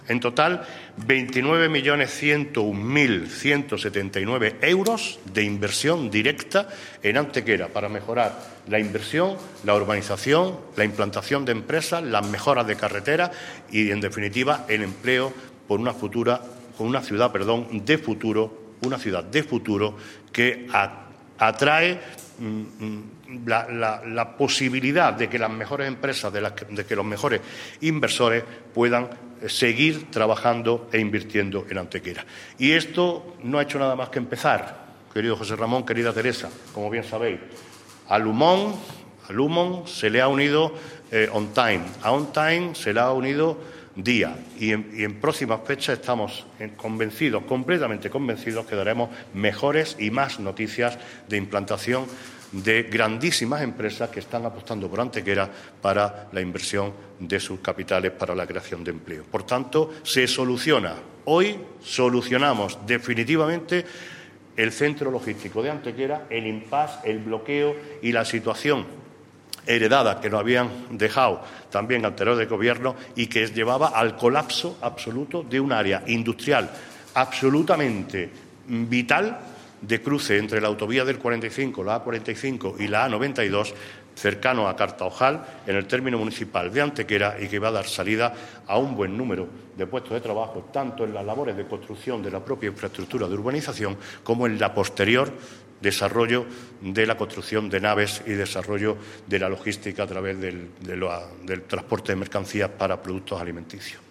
El alcalde de Antequera, Manolo Barón, acompañado por la teniente de alcalde de Urbanismo, Teresa Molina, y del concejal delegado de Obras e Inversiones, José Ramón Carmona, ha informado hoy en rueda de prensa de la aprobación (jurídica, administrativa y urbanísticamente) de un millón y medio de metros cuadrados de nuevo suelo industrial solucionando así tras más de una década el bloqueo del Centro Logístico de Antequera.
Cortes de voz